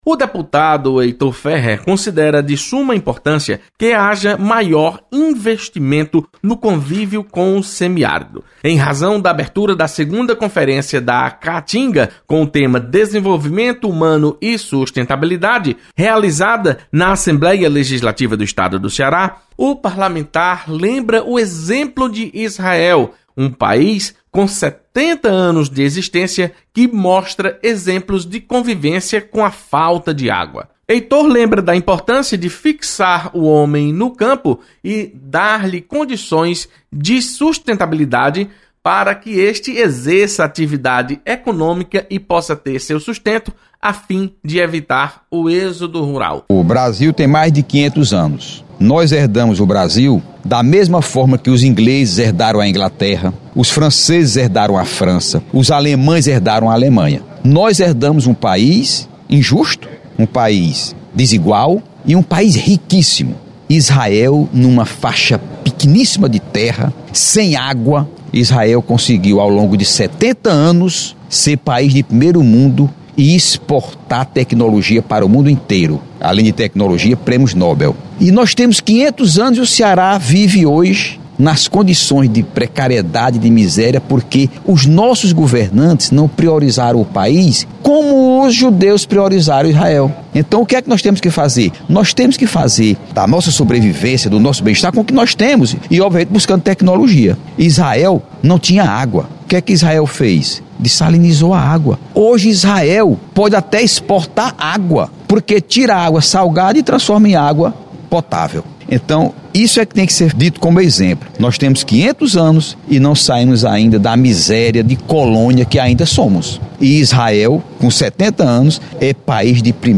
Deputado Heitor Férrer acredita que exemplo de Israel deve ser seguido pelos estados do Semiárido Nordestino. Repórter